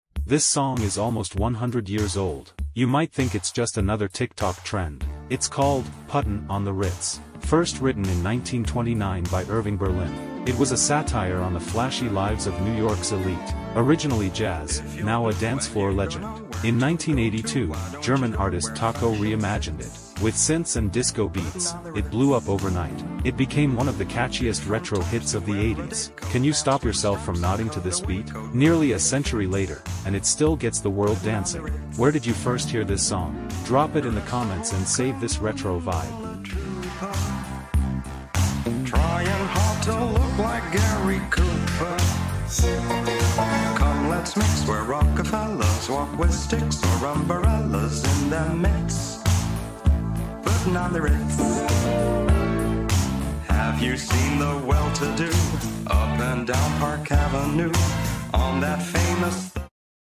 From 1929 jazz to 1982 disco